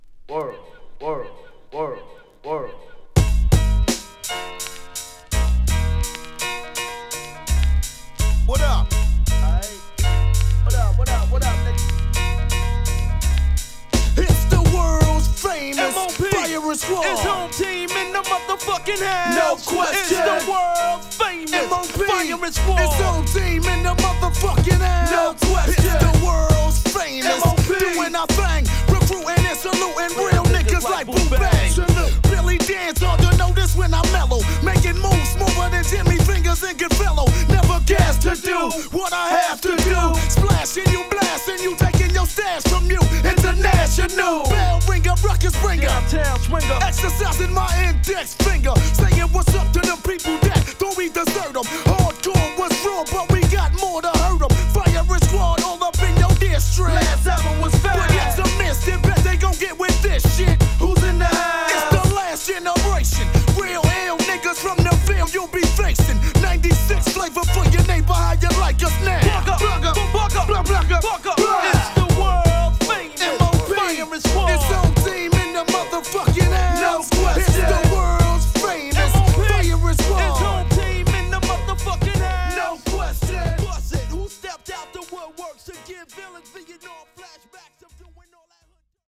(Instrumental Version)